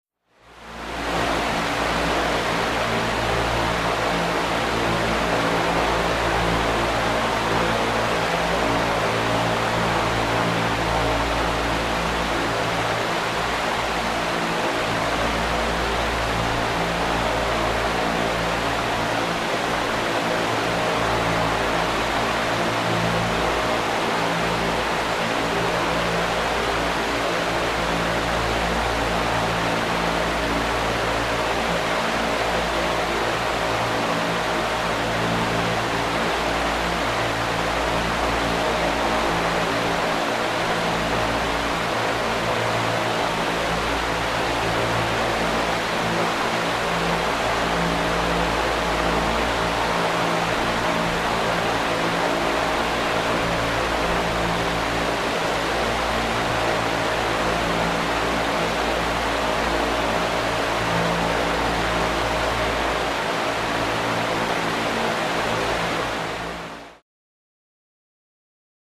Large Industrial Fan Runs Steady At High Speed, Cu Perspective, W Motor Hum.